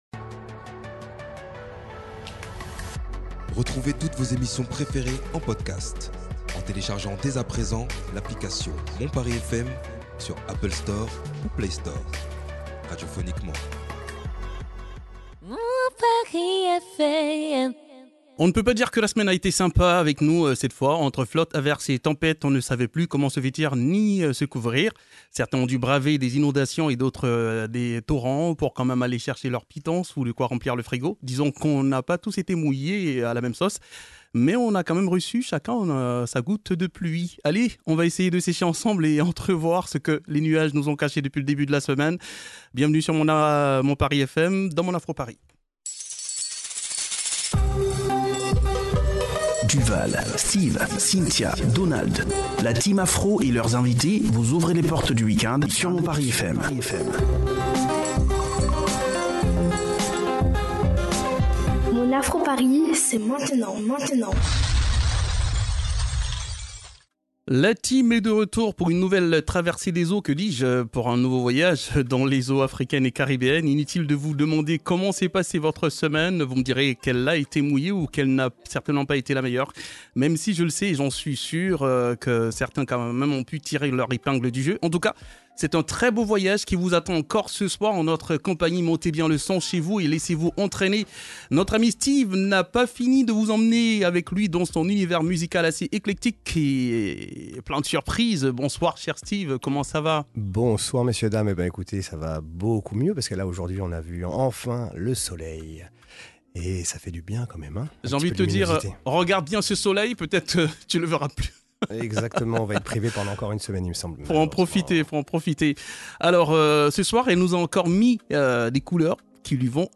Le tout dans une ambiance décontractée et bon enfant pour un début de Week end réussi.